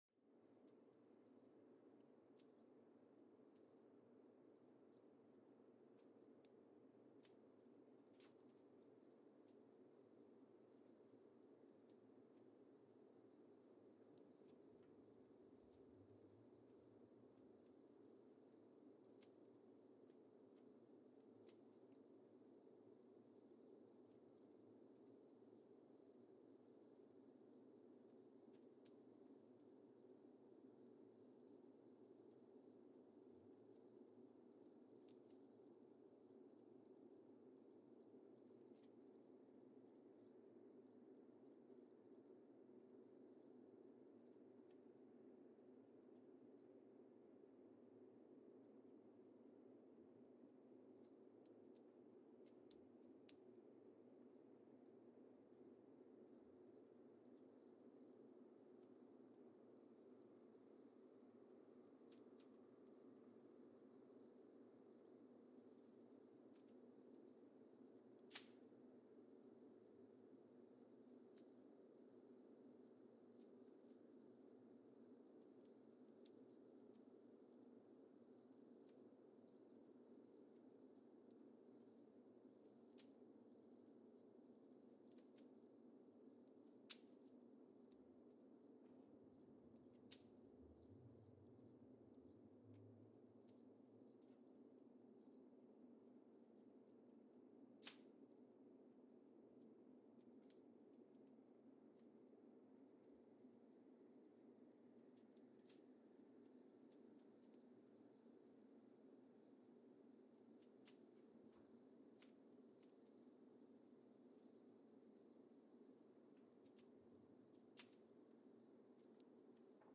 Mbarara, Uganda (seismic) archived on August 21, 2020
No events.
Sensor : Geotech KS54000 triaxial broadband borehole seismometer
Speedup : ×1,800 (transposed up about 11 octaves)
Loop duration (audio) : 05:36 (stereo)